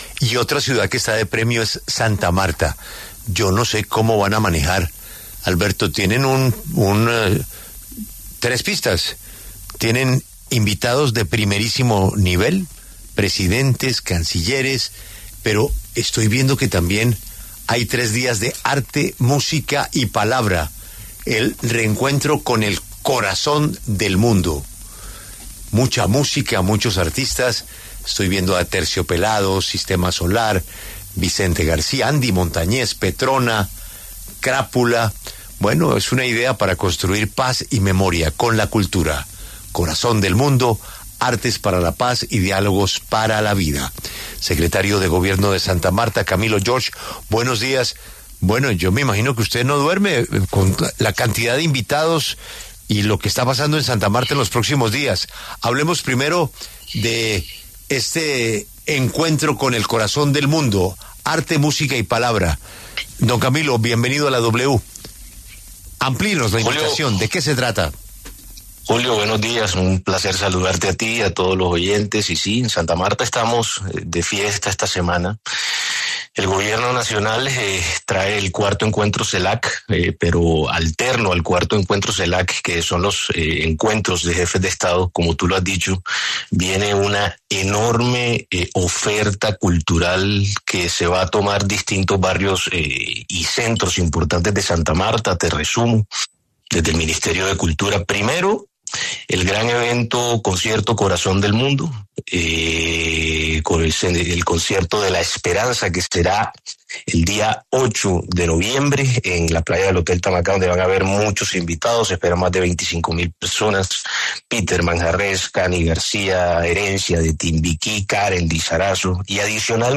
El secretario de Gobierno del Distrito, Camilo George, en diálogo con La W entregó detalles de la logística y organización del encuentro de los jefes de estado y las actividades culturales que se tomarán distintos lugares de la ciudad.